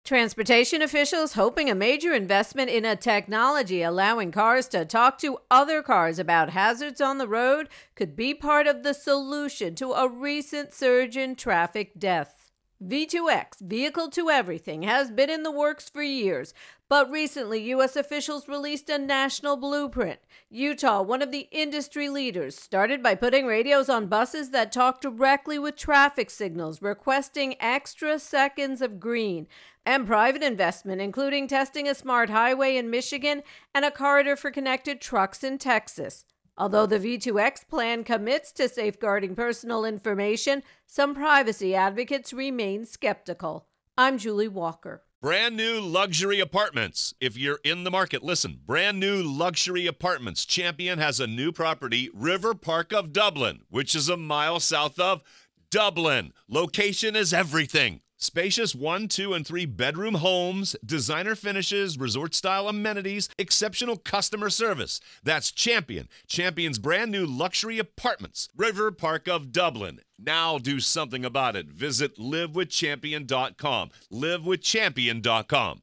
reports on cars talking to one another and how it could ultimately help reduce crashes.